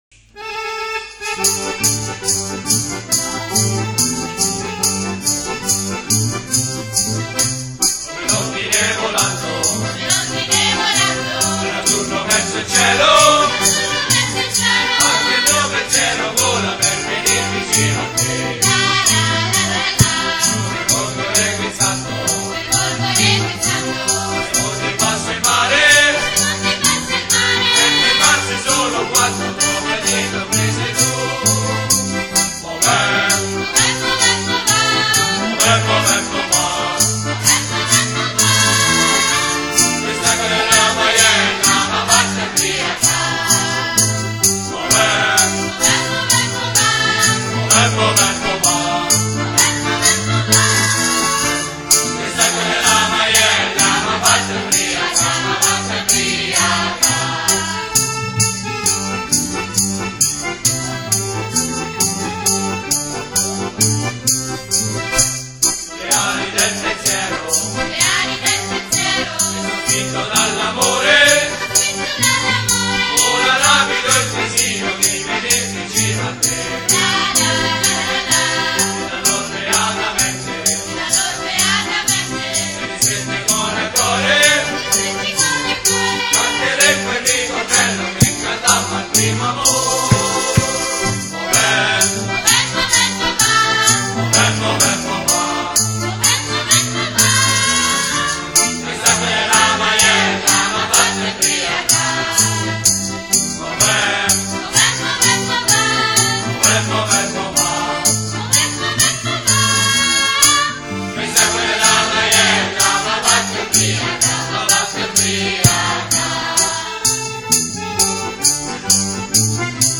I canti antichi regionali, la rievocazione tramite balli di scene di vita agreste, di vecchi mestieri e di tradizioni popolari, caratterizzano le performances dei “Dragoni del Molise”.